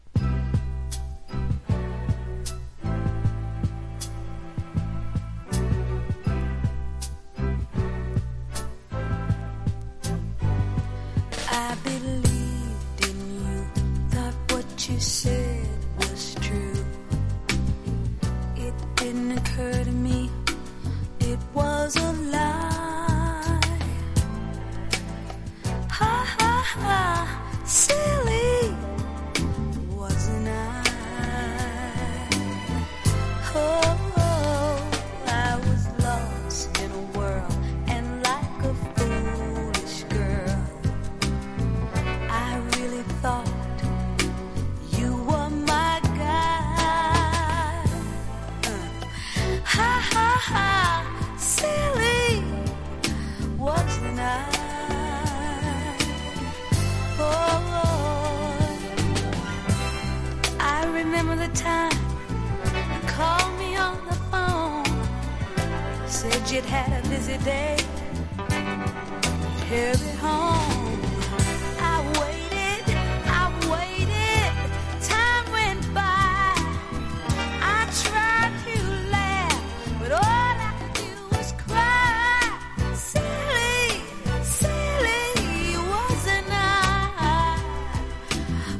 しっとりと派手すぎないモータウンクラシックス！